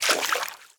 Footstep_Water_04.wav